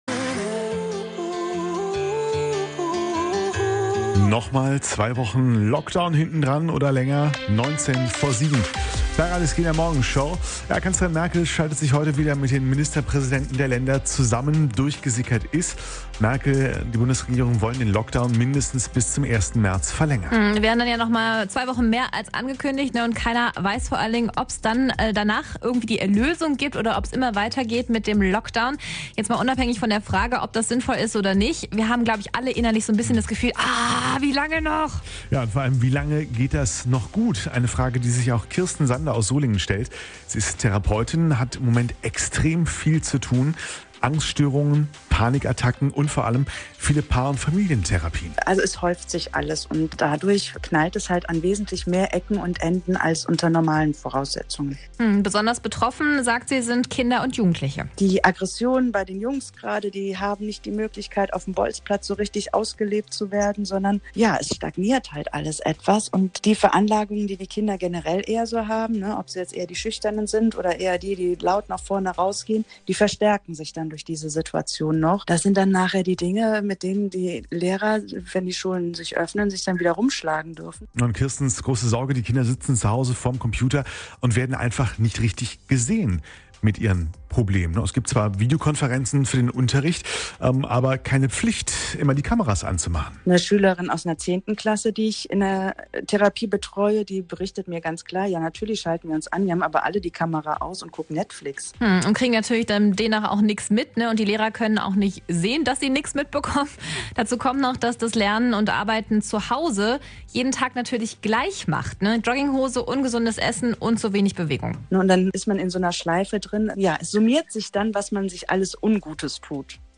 Anzeige play_circle play_circle RSG-Morgenshow Lockdown belastet die Psyche download play_circle Abspielen download Anzeige